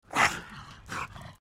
Звуки мопса
Звук кашляющего мопса